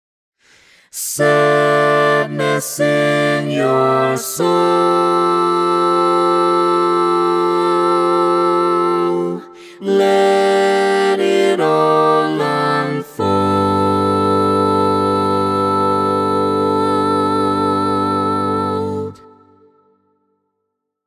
Key written in: D Minor
How many parts: 4
Type: SATB
All Parts mix:
Learning tracks sung by